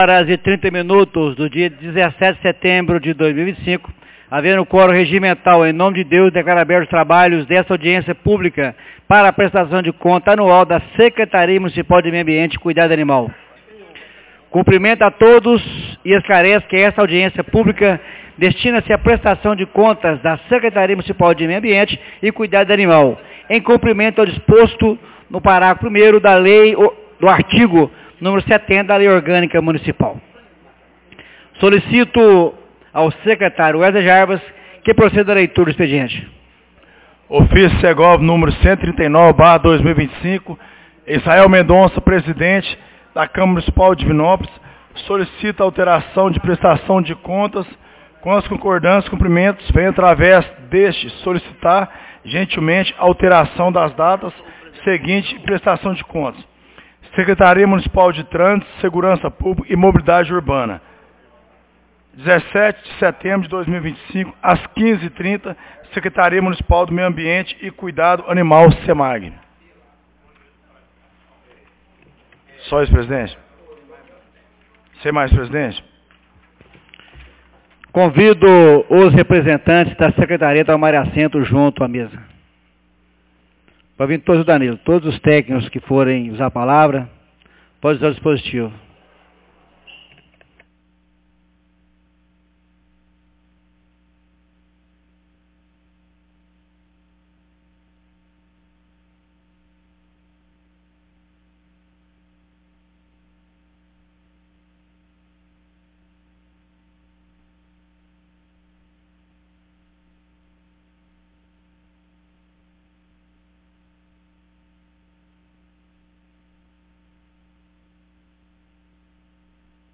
Audiencia publica Prestação de contas da Sec Mun de meio Ambiente e cuidado animal 17 de setembro de 2025